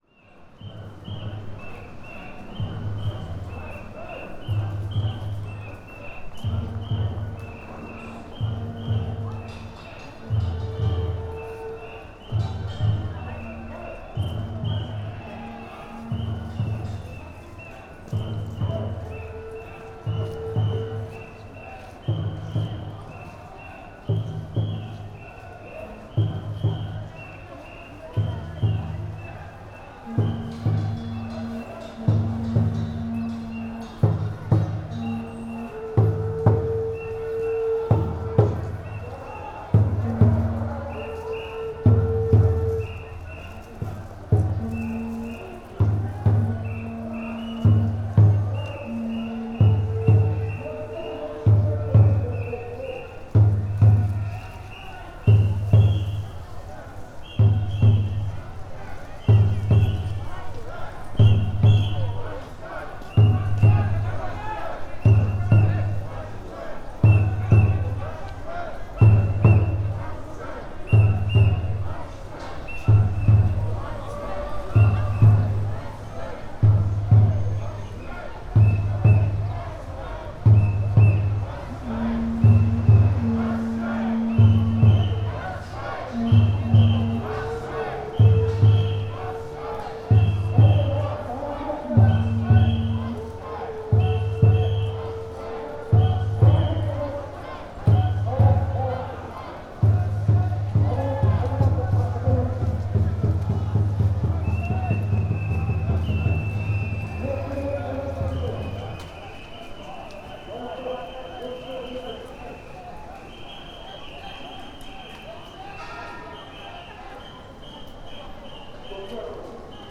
Fukushima Soundscape: Station road